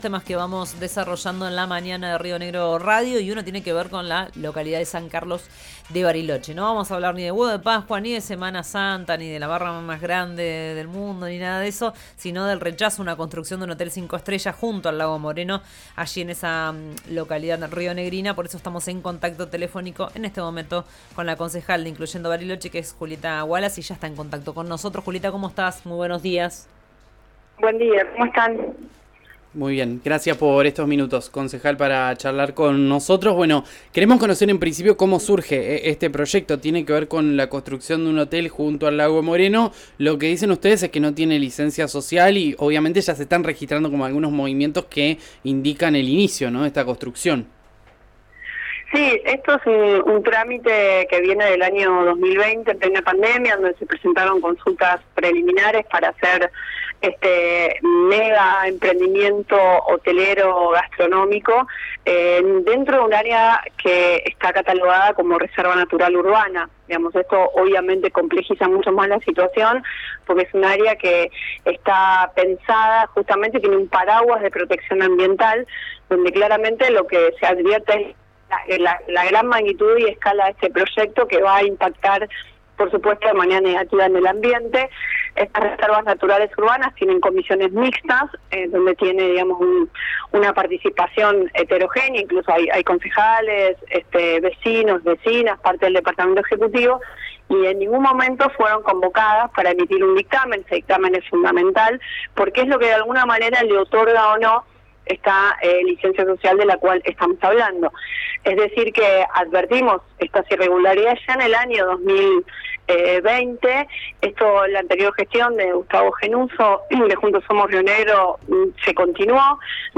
Escuchá a Julieta Wallace, concejal de Incluyendo Bariloche, en RÍO NEGRO RADIO: